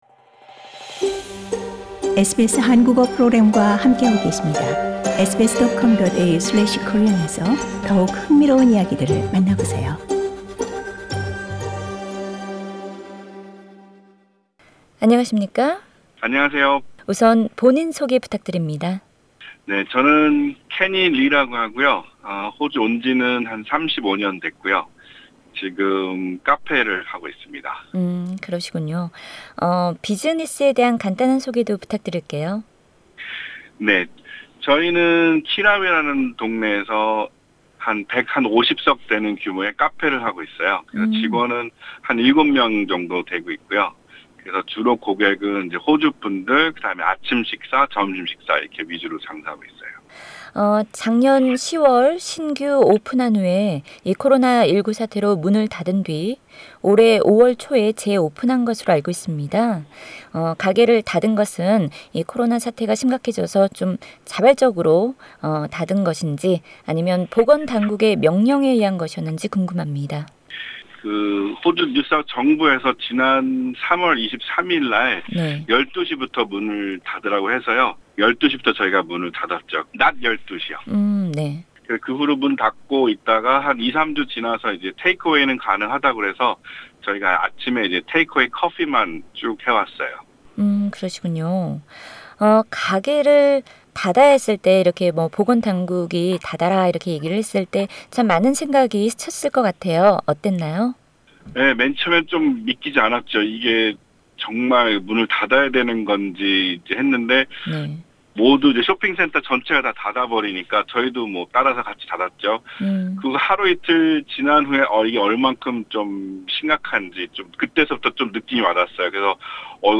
SBS [전체 인터뷰 내용은 상단의 팟 캐스트를 통해 들으실 수 있습니다] 뉴사우스웨일스 주정부는 지난 6월 사업체를 위한 COVID Safe Check 온라인 툴을 선보였다.